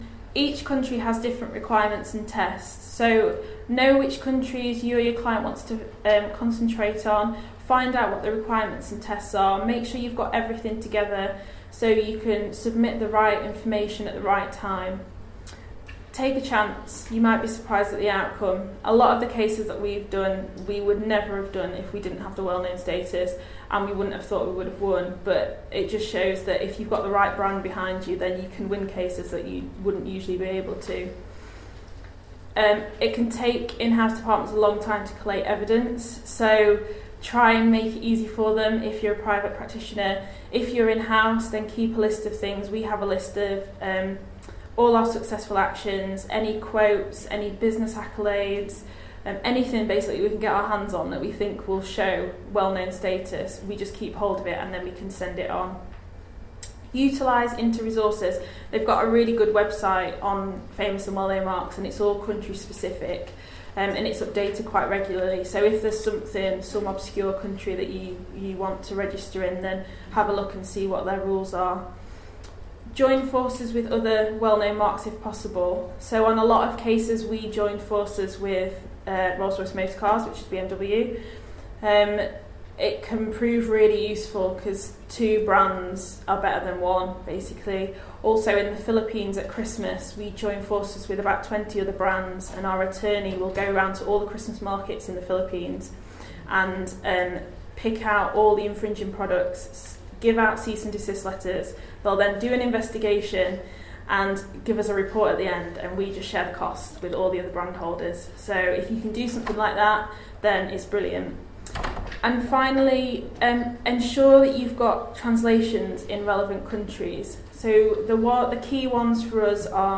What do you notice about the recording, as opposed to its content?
Recorded at the Institute of Trade Mark Attorneys's Autumn Seminar in September 2015.